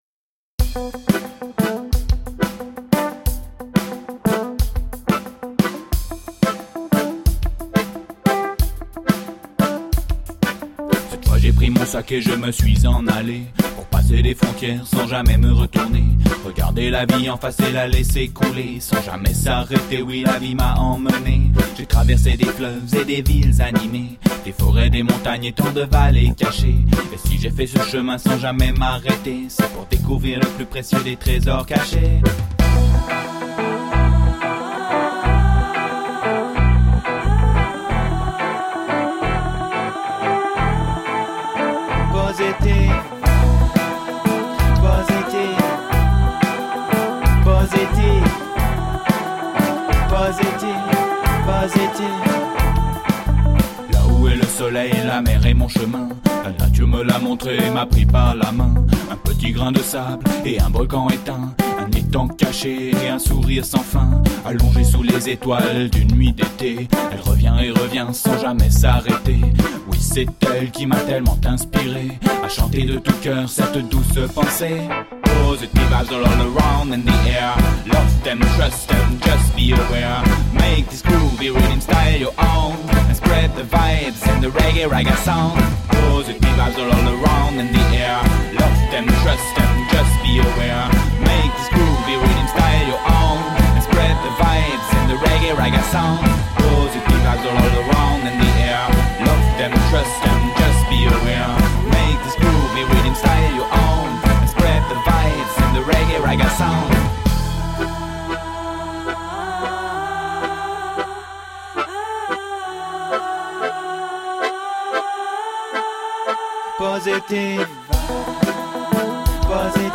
Dub, reggae, hip hop and world music from the heart.